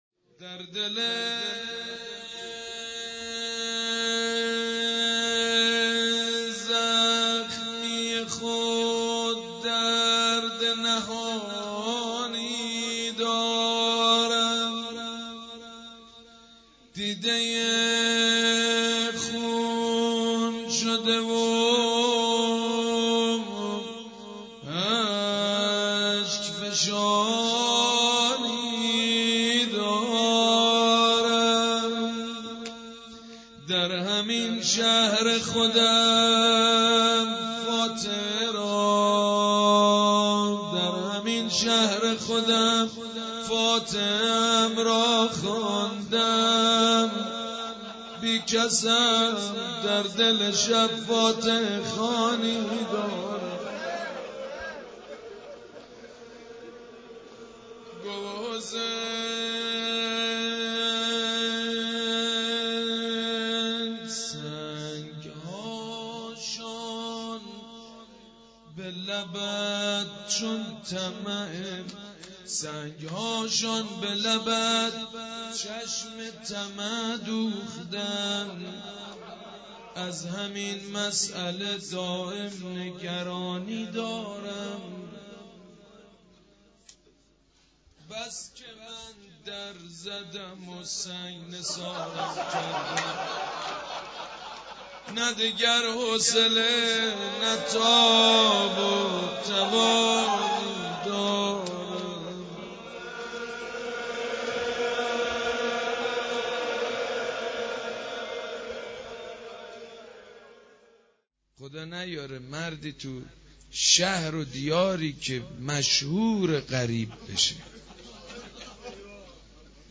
شب اول محرم 93